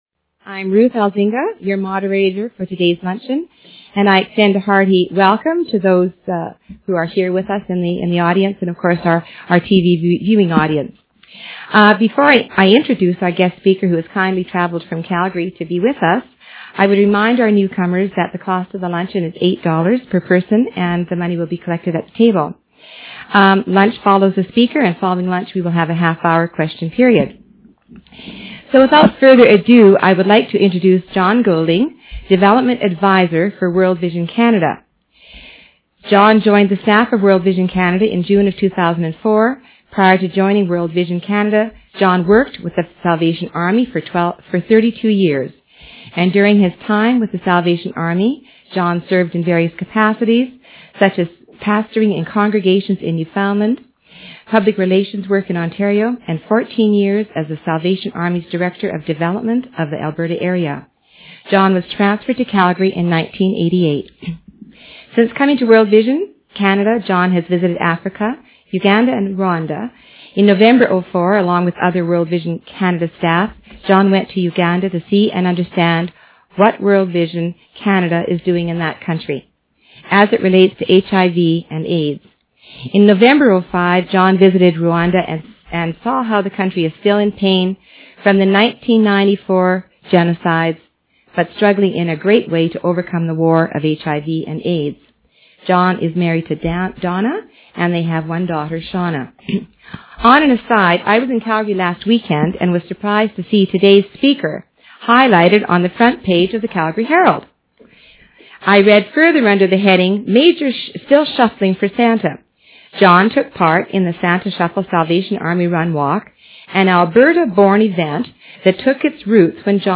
Location: Sven Ericksen''s Family Restaurant, 1715 Mayor Magrath Drive S.